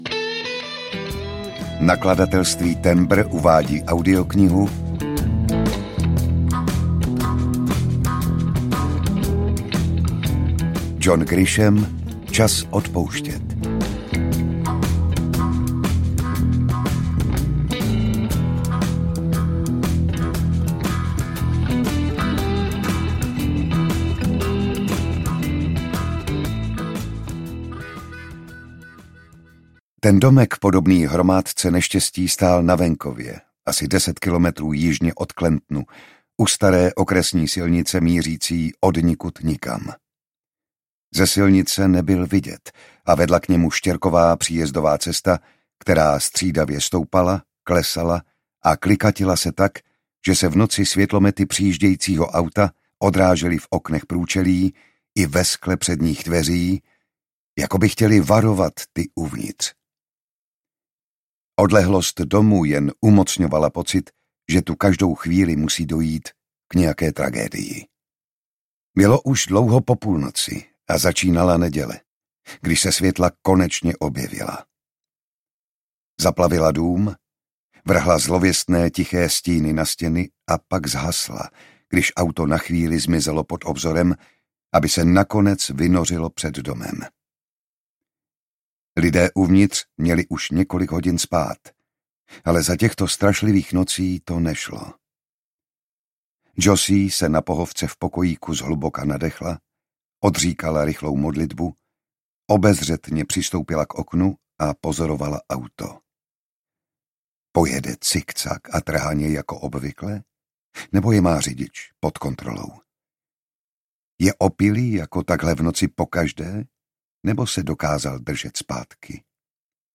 Čas odpouštět audiokniha
Ukázka z knihy
• InterpretJan Šťastný